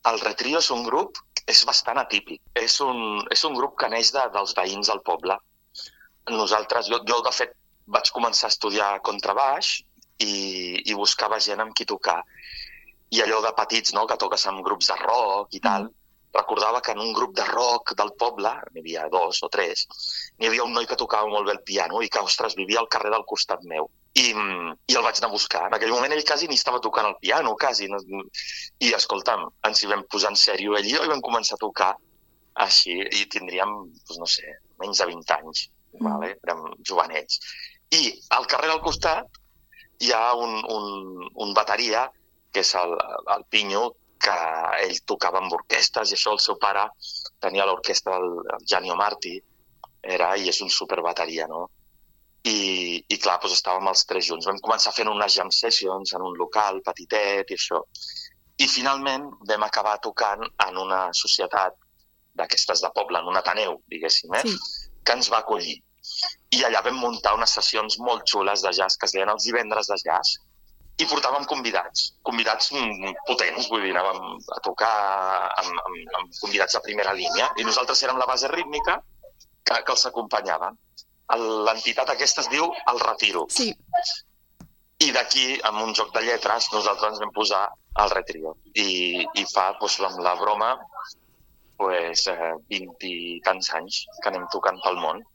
amb qui fem l’entrevista.